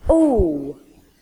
ow